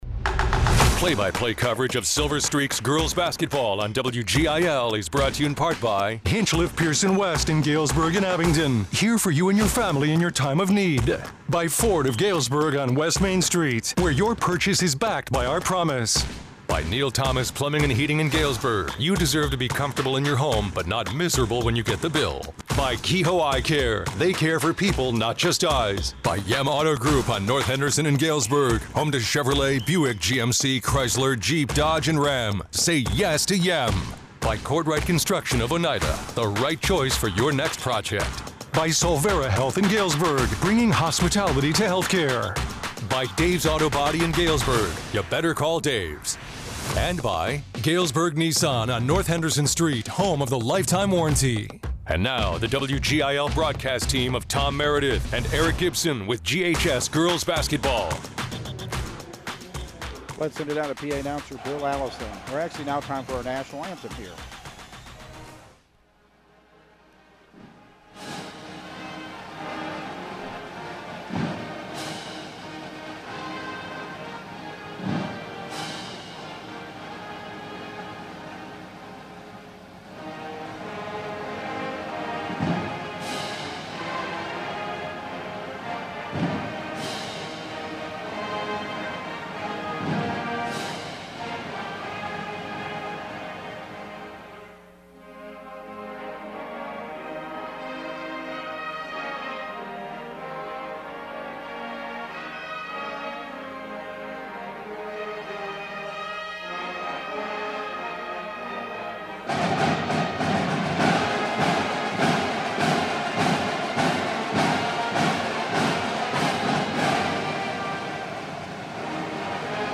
The Galesburg Silver Streaks girls basketball team lost a hard-fought non-conference game on Saturday (1/21) at John Thiel Gym to the Morton Potters, 41-38.